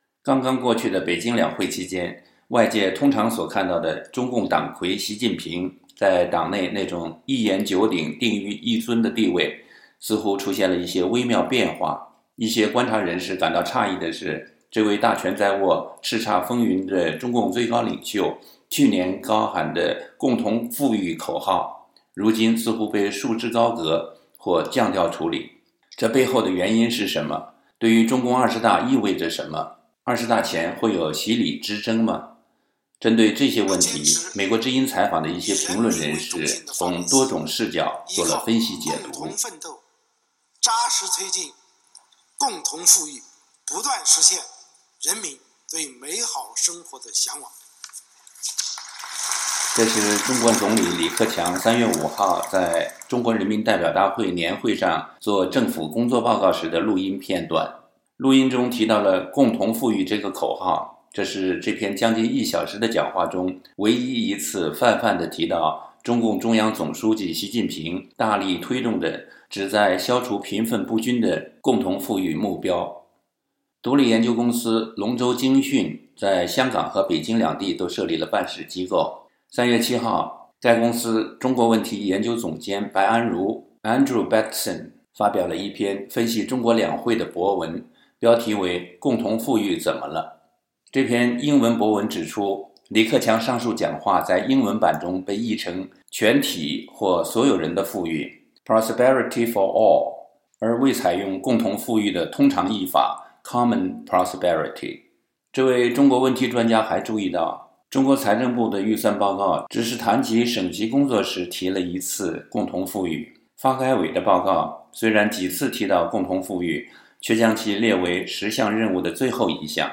这背后的原因是什么？对于中共20大意味着什么？20大前会有习李之争吗？针对这些问题，美国之音采访的一些评论人士从多种视角作了分析解读。